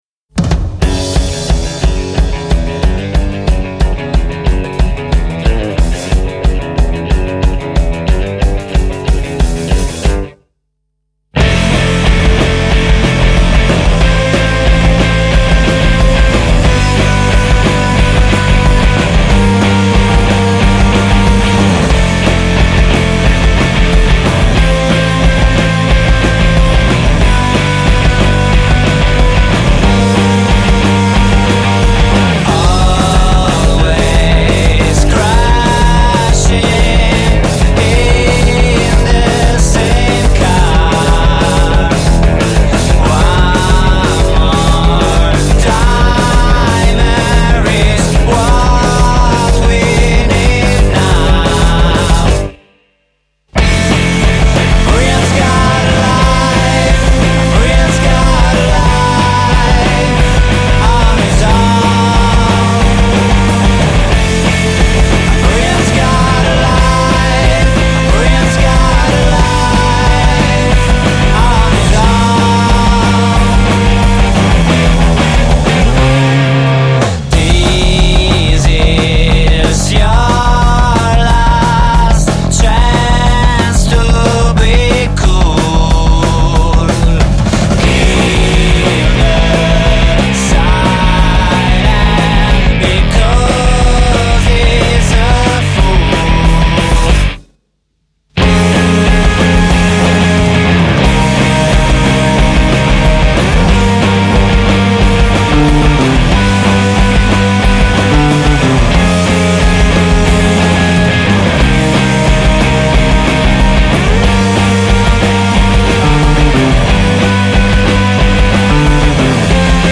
guitars and basses
drums
vocals
power-rock band